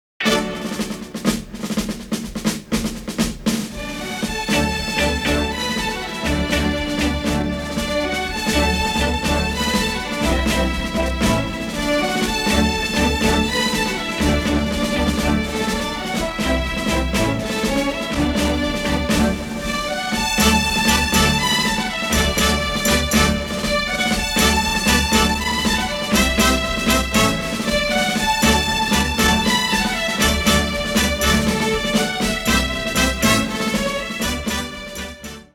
memorable, energetic and varied score